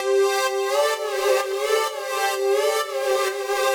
Index of /musicradar/french-house-chillout-samples/128bpm/Instruments
FHC_Pad C_128-C.wav